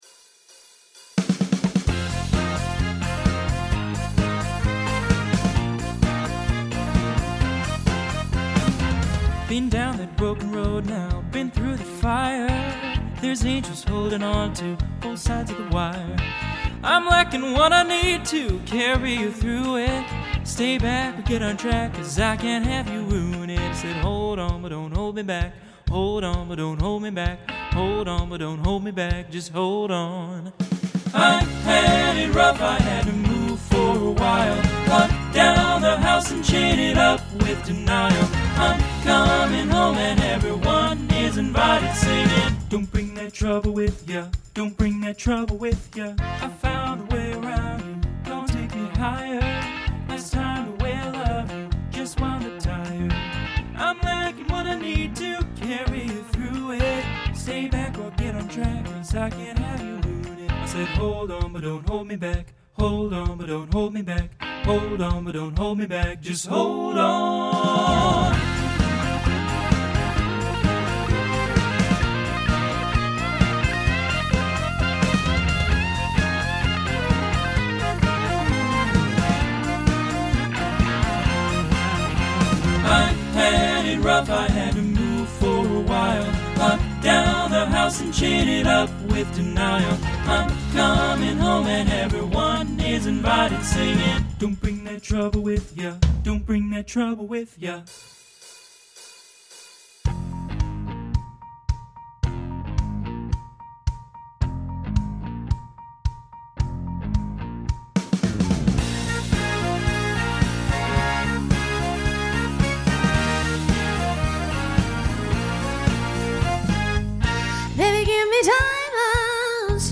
TTB/SSA
Instrumental combo
Pop/Dance , Rock